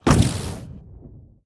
Minecraft Version Minecraft Version 25w18a Latest Release | Latest Snapshot 25w18a / assets / minecraft / sounds / entity / shulker / shoot1.ogg Compare With Compare With Latest Release | Latest Snapshot
shoot1.ogg